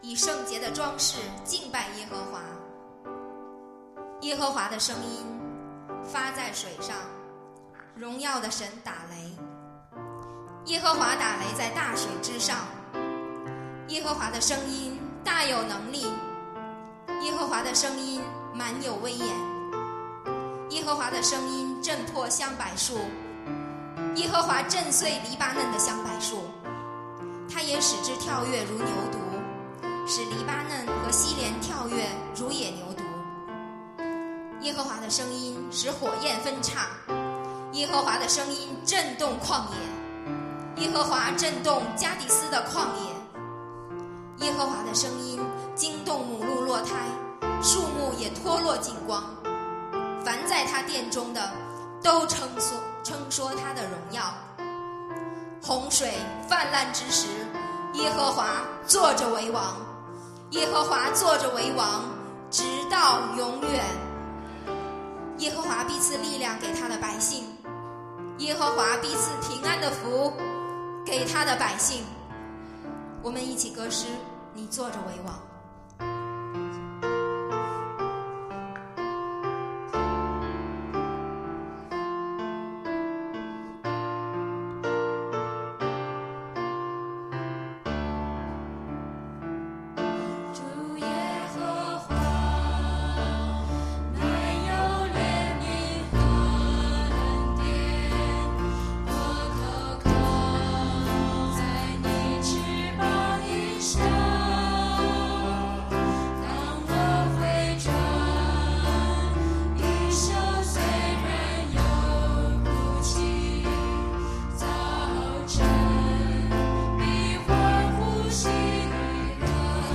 北京守望教会祷告部赞美组 2014年终敬拜赞美会 – 2014 end of year worship of Worship Band of Beijing Shouwang Church Prayer Service